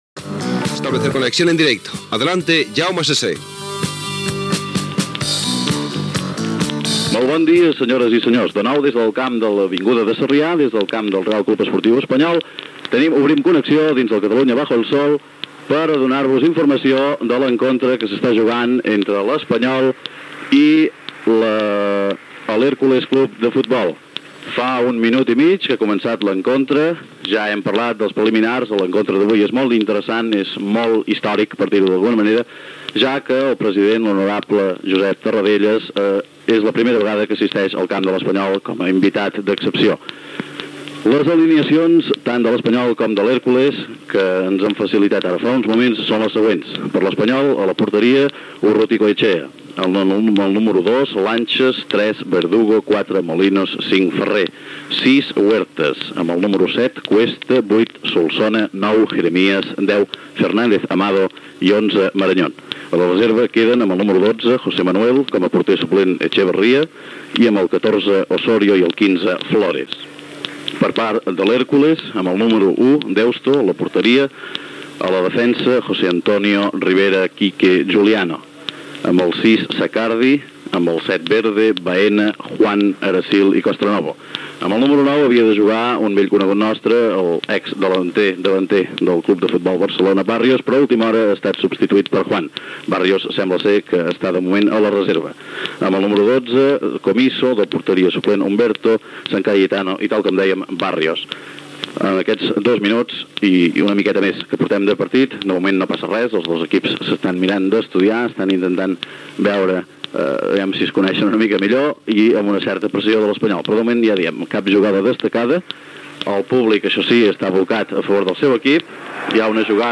Connexió amb el camp de l'Avinguda de Sarrià on es juga el partit de futbol masculí entre el Reial Club Esportiu Espanyol i l'Hércules Club de Futbol. Narració de l'ambient al camp i de les primeres jugades de la primera part.